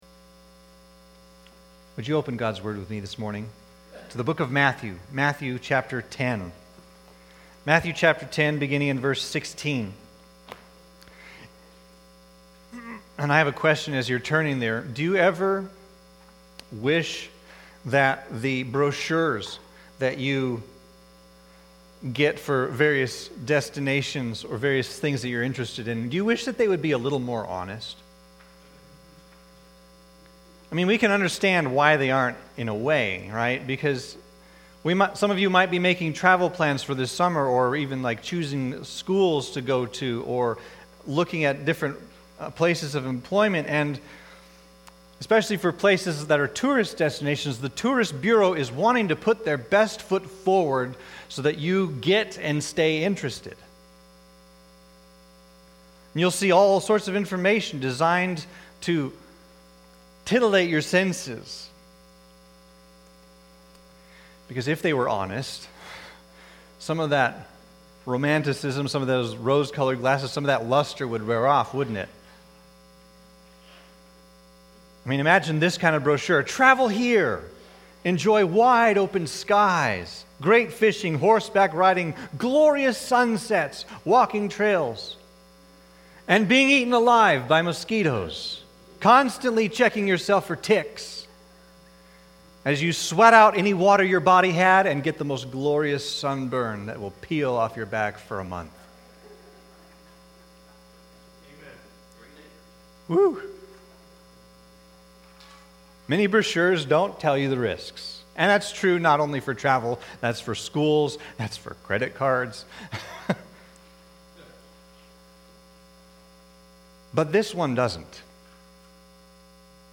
Sermons | York Evangelical Free Church